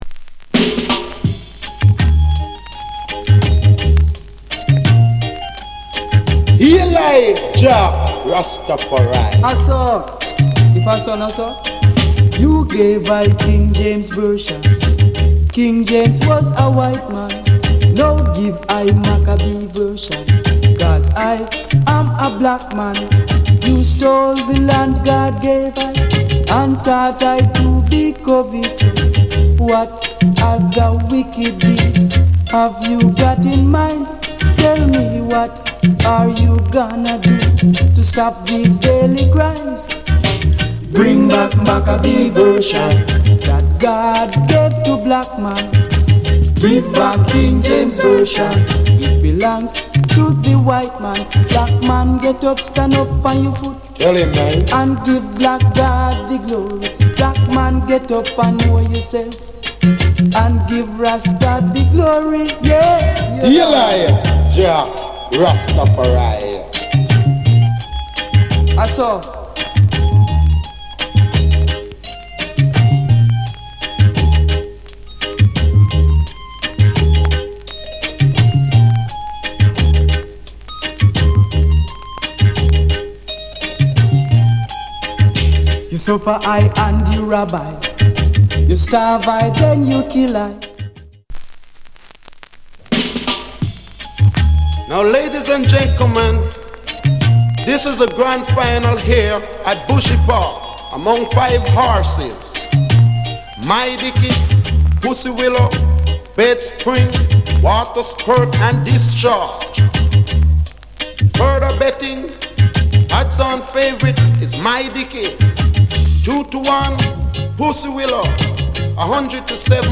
Genre Reggae70sEarly / [A] Male Vocal [B] Male DJ